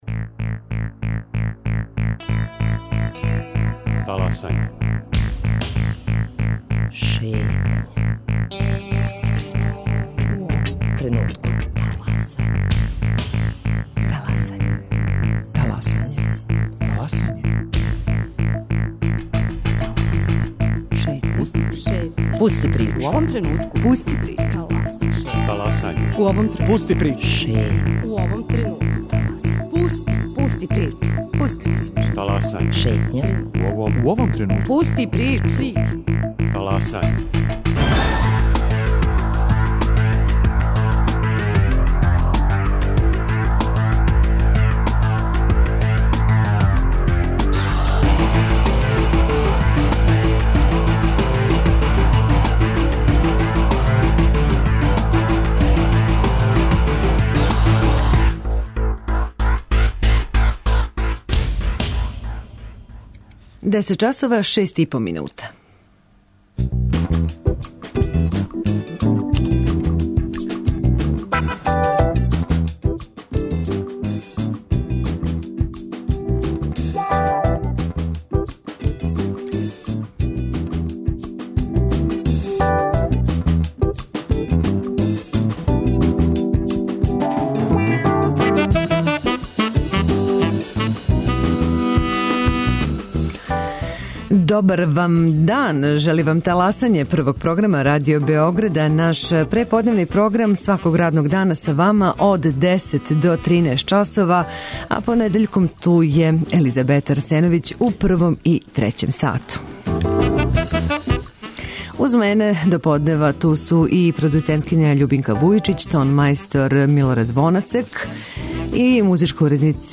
Горући проблеми који тиште грађане односе се на радне односе. О томе, али и другим областима рада, говори заштитница грађана Војводине, Анико Мушкиња Хајнрих.